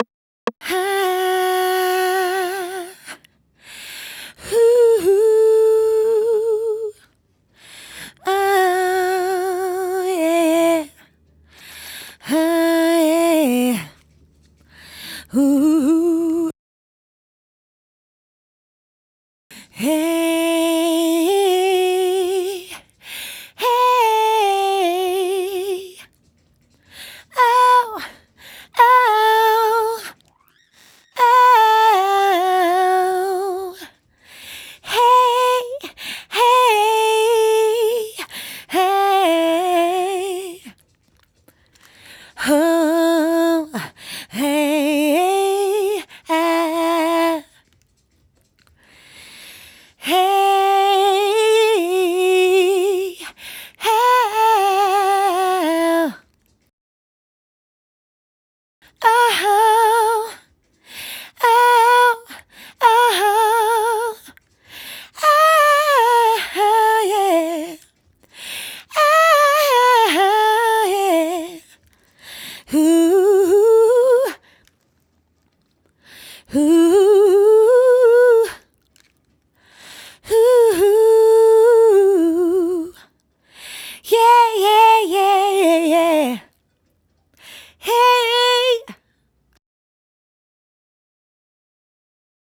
2 - Away (127BPM)
Ad-Lib .wav